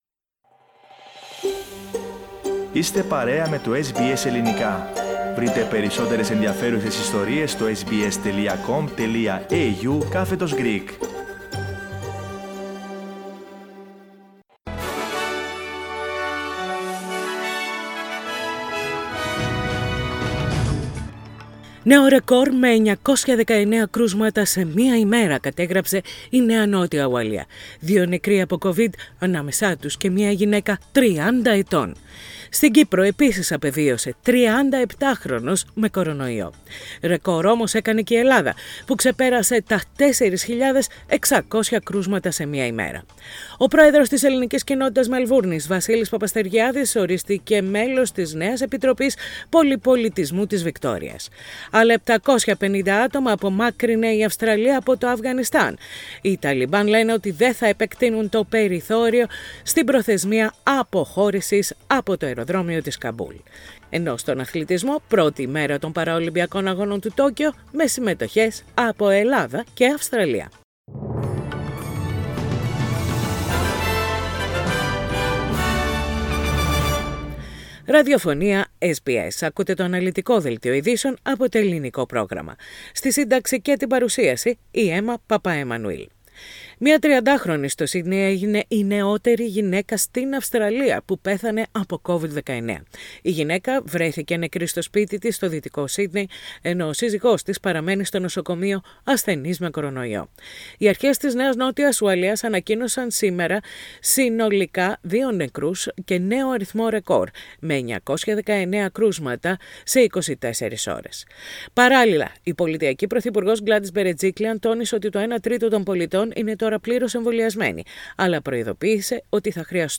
Ειδήσεις στα Ελληνικά - Τετάρτη 25.8.21
Οι κυριότερες ειδήσεις της ημέρας από Αυστραλία, Ελλάδα, Κύπρο και τον διεθνή χώρο. Όπως παρουσιάστηκαν στο Ελληνικό πρόγραμμα της ραδιοφωνίας SBS.